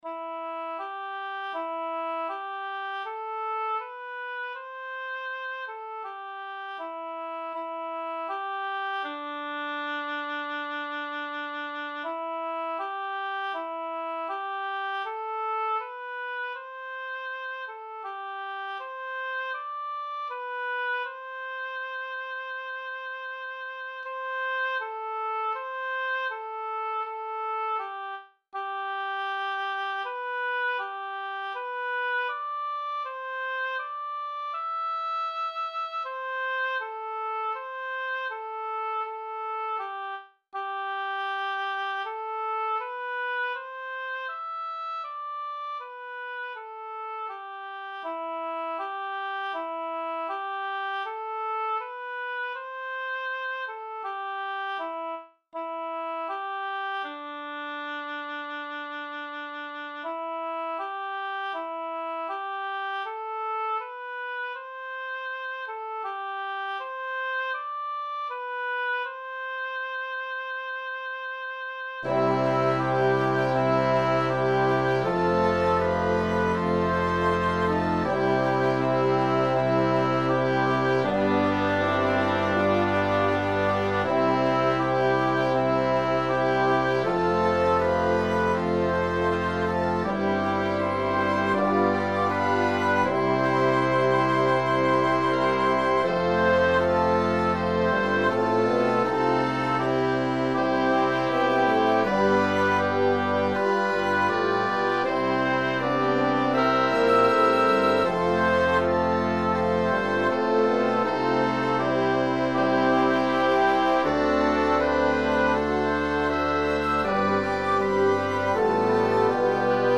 Made with an M-Audio Keystation 49-e keyboard and the FINALE composition program.